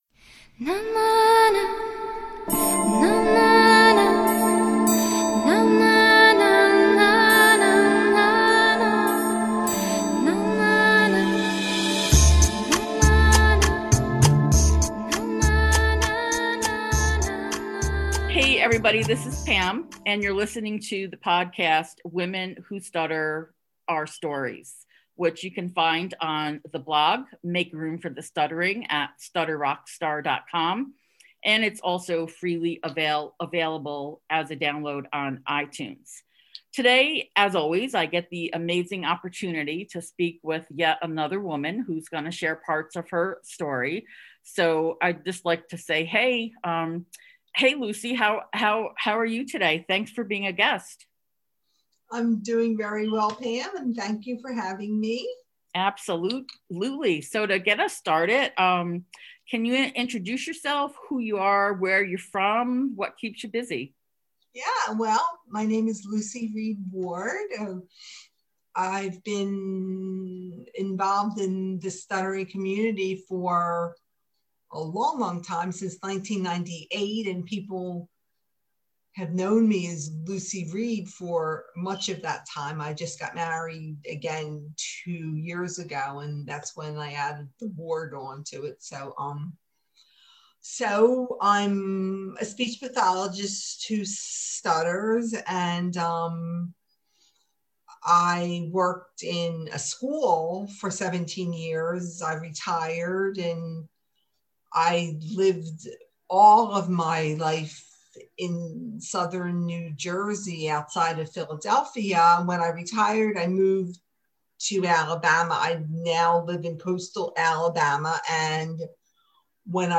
Listen in to this great conversation about covert stuttering, kicking shame and fear to the curb, and her experiences with her own therapy journey.